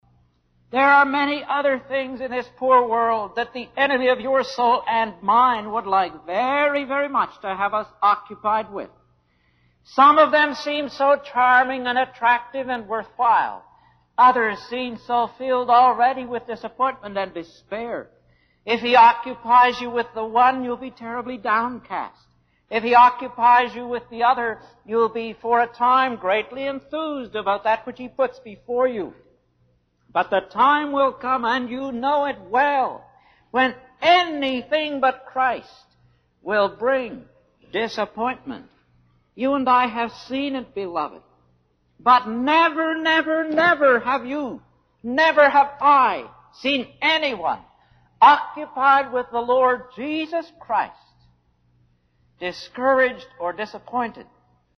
They go from the 1960s to the 80s, are of varying degrees of sound quality, but are pretty much all giving exactly the same message, despite there being about sixty of them.